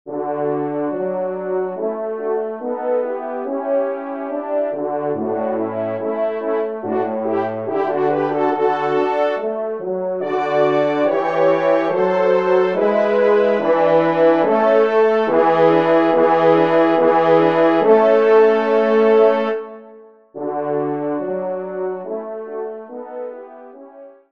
24 compositions pour Trio de Cors ou de Trompes de chasse